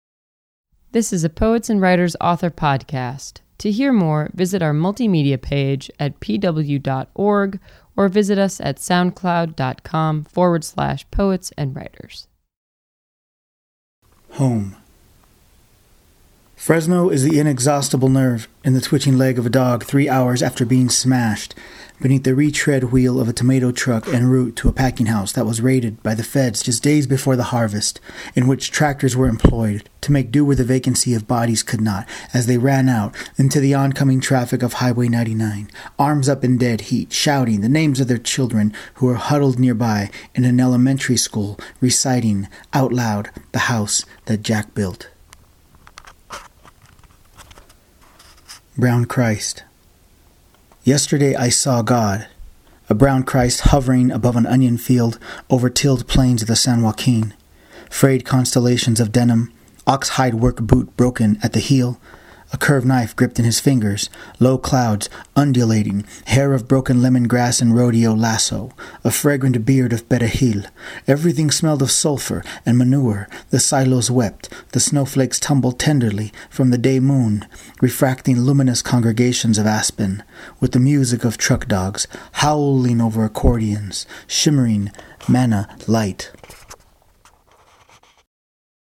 reads the poems “Home” and “Brown Christ” from his collection
poetry